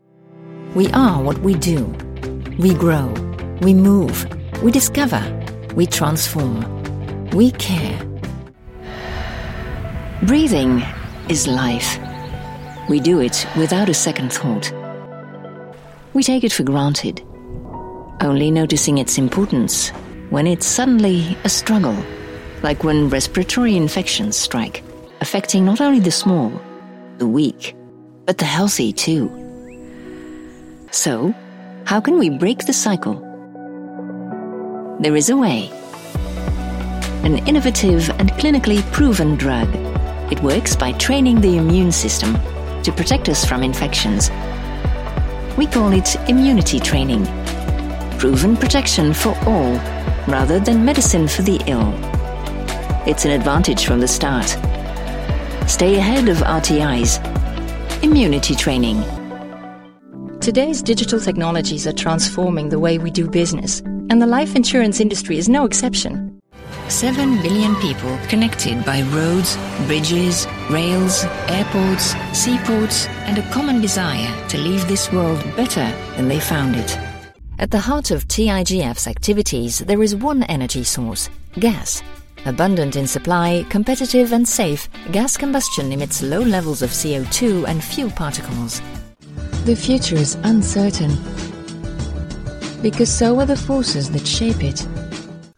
Corporate Videos
Multilingual professional with an international sound.
StudioBricks One booth, AT4047 and Shure KSM32 microphones, Audient iD14 interface, Izotope, Mac.
Mezzo-Soprano